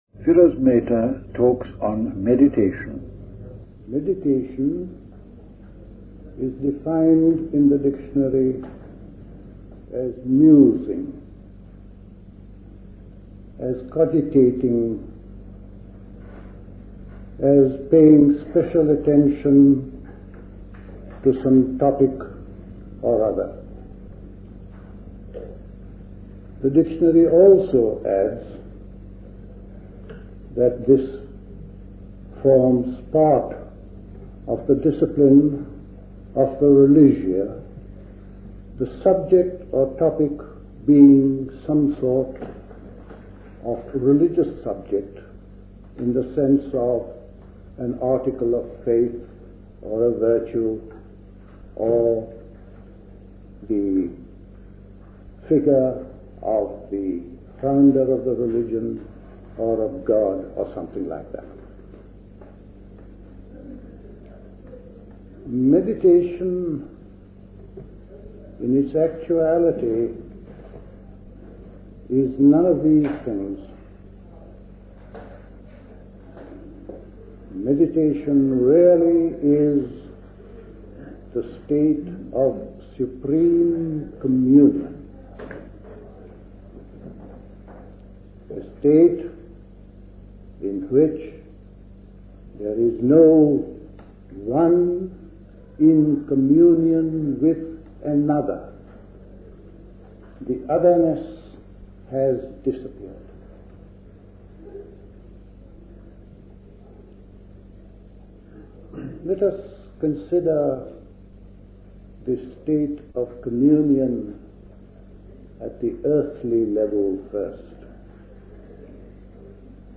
Recorded at the 1975 Buddhist Summer School.